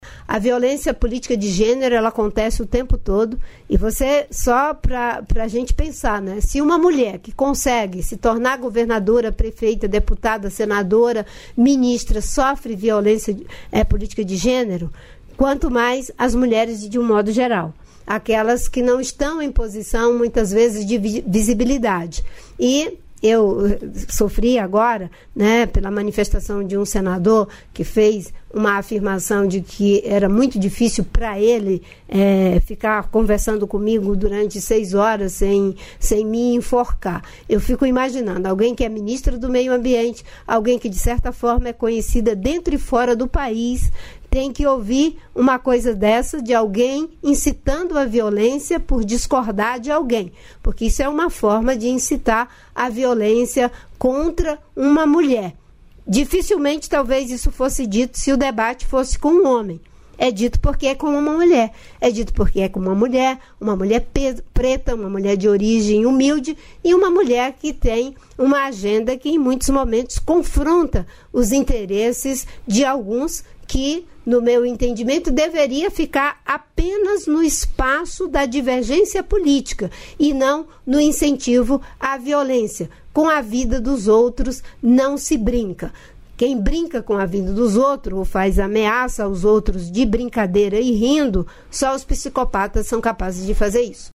Trecho da participação da ministra do Meio Ambiente e Mudança do Clima, Marina Silva, no programa "Bom Dia, Ministra" desta quarta-feira (19), nos estúdios da EBC em Brasília.